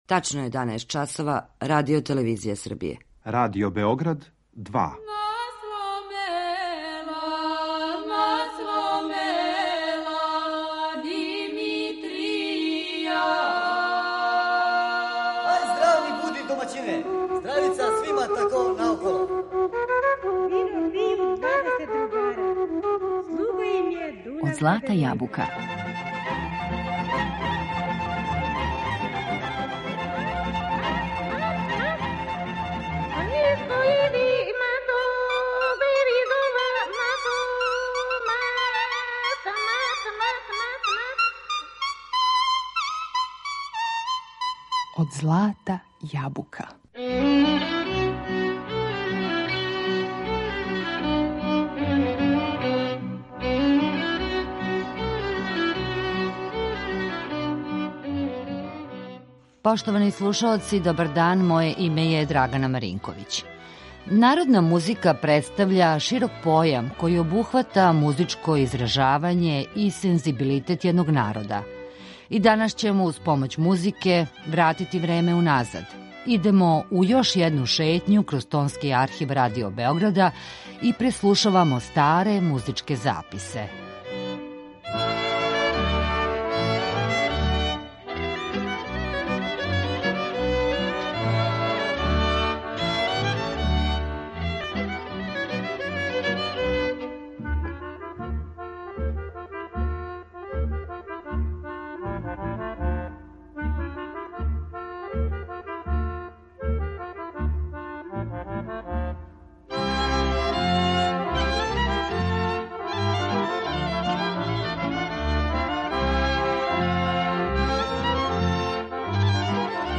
У данашњој емисији Од злата јабука, уз помоћ записа који се налазе у Звучном архиву Радио Београда, настављамо нашу музичку шетњу кроз време.